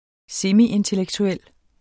Udtale [ ˈsemi- ]